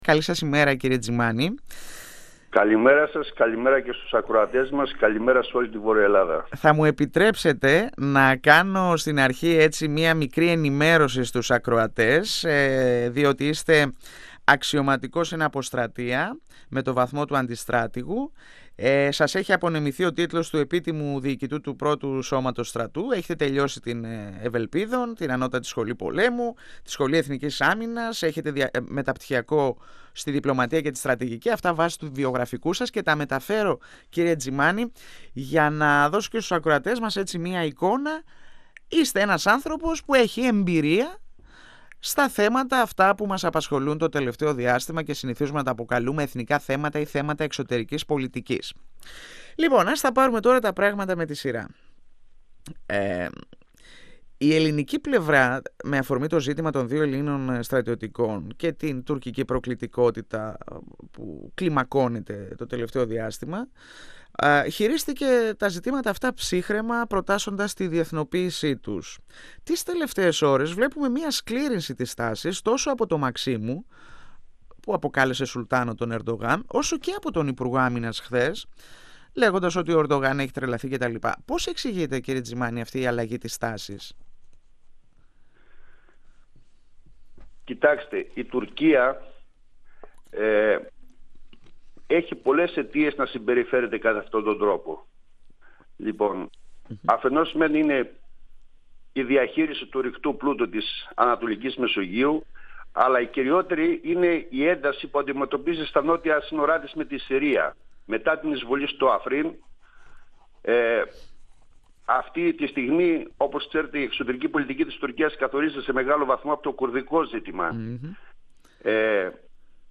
Στο υψηλό ηθικό και το αξιόμαχο των ελληνικών ενόπλων δυνάμεων αναφέρθηκε ο βουλευτής Κοζάνης του ΣΥΡΙΖΑ Γιώργος Ντζιμάνης μιλώντας στον 102 fm της ΕΡΤ3.
Συνέντευξη